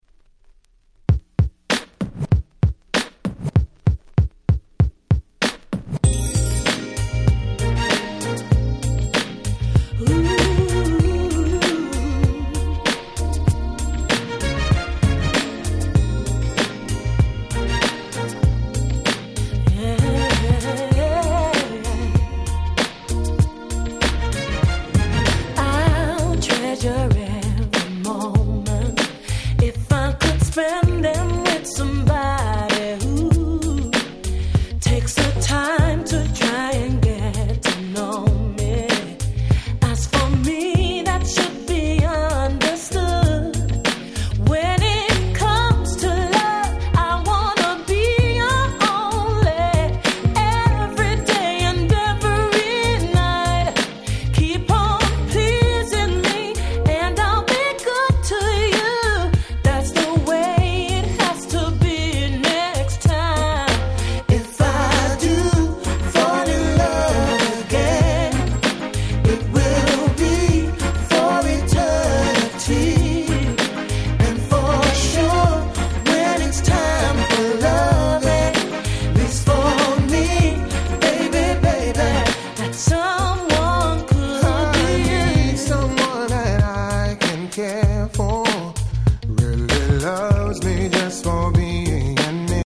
02' Nice UK R&B !!